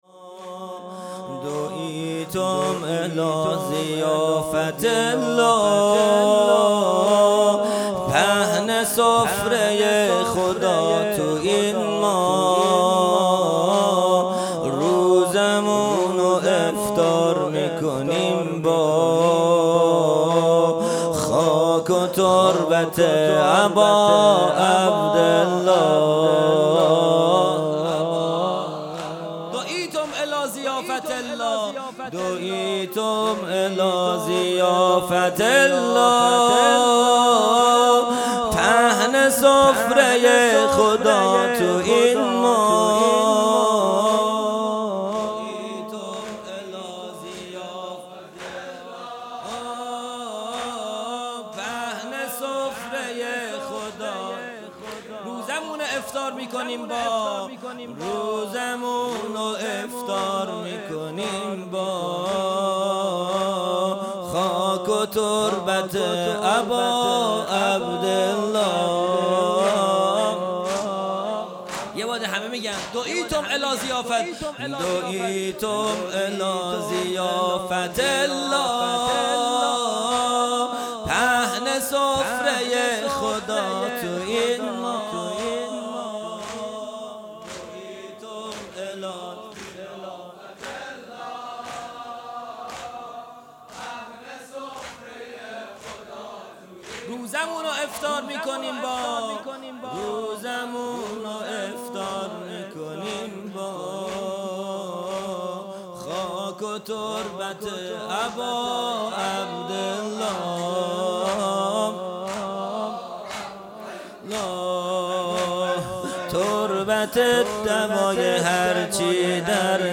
زمینه | دُعیتُمْ إِلَی ضِیافَةِ اللَّه | 25 فروردین 1401
جلسۀ هفتگی | مناجات ماه رمضان | پنج شنبه 25 فروردین 1401